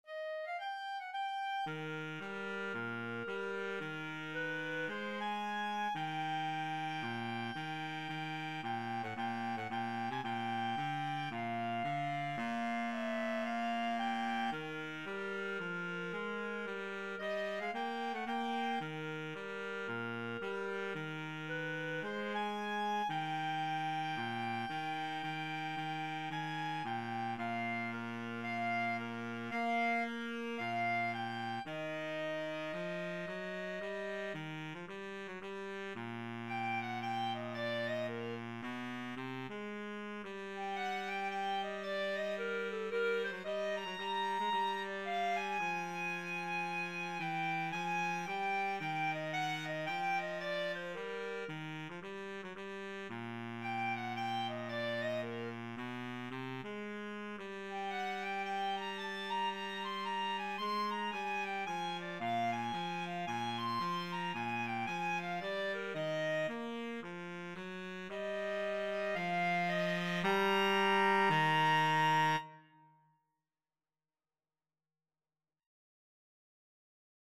Free Sheet music for Clarinet-Saxophone Duet
ClarinetBaritone Saxophone
4/4 (View more 4/4 Music)
Eb major (Sounding Pitch) F major (Clarinet in Bb) (View more Eb major Music for Clarinet-Saxophone Duet )
Moderato = c. 112
Jazz (View more Jazz Clarinet-Saxophone Duet Music)